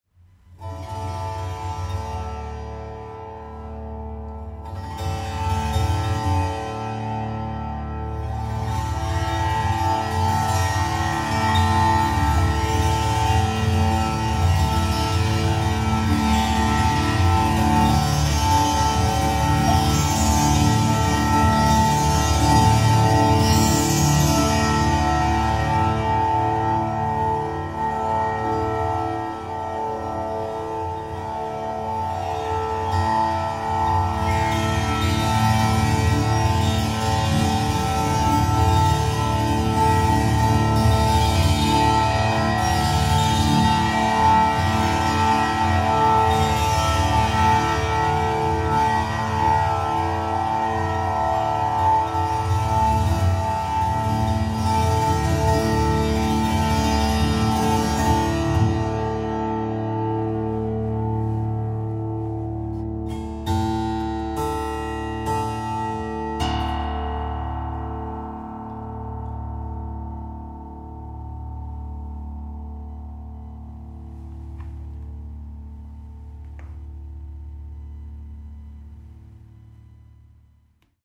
Diese mit Silber umsponnene Saite oktaviert den Grundton des Monochords (im Allgemeinen: D) noch einmal nach unten und erzeugt so Schwingungen von etwa 36,5Hz (Kontra-D, oder D1)
Das Klangspektrum erweitert sich dadurch nach unten, sanft, aber wirkungsvoll.
beidseitig Tambura-Stimmung (D/A) auf 135cm, flacher Korpus, je 1 Subbass-Saite